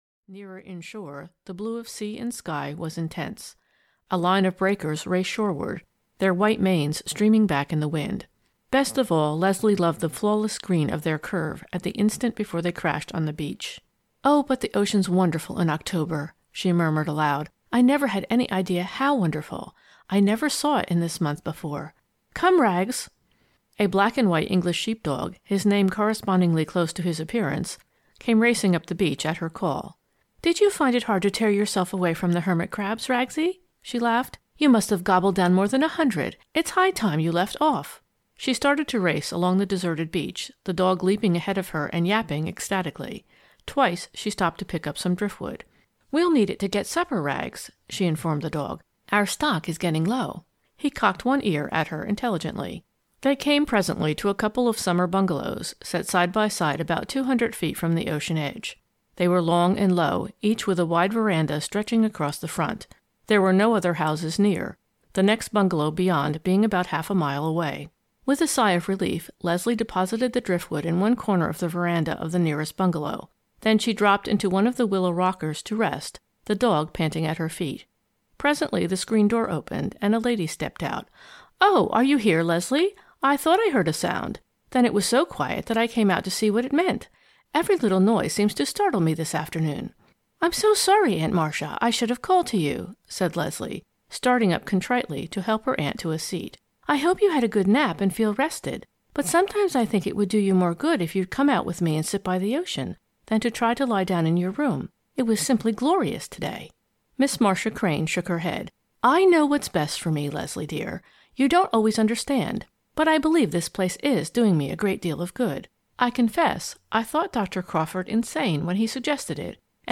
The Dragon's Secret (EN) audiokniha
Ukázka z knihy